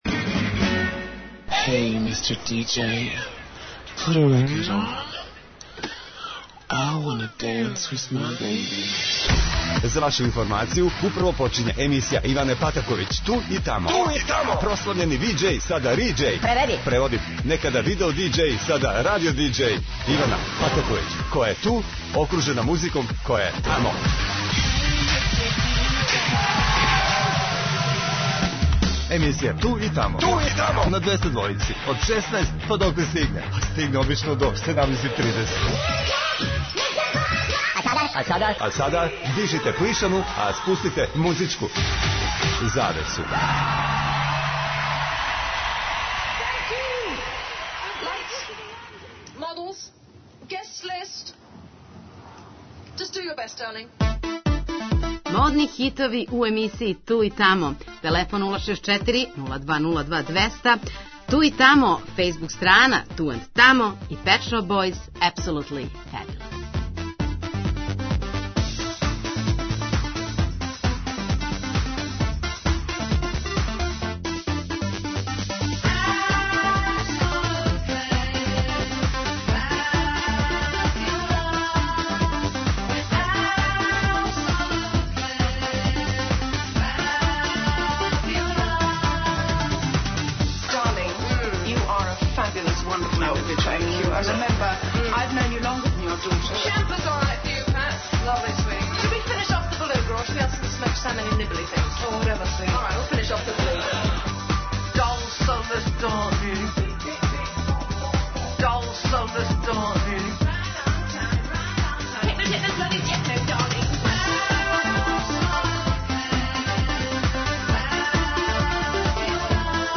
Ове суботе емисија је у модном фазону!!! Завртеће се песме које у наслову имају нешто модерно, фенси, или једноставно 'фешн'.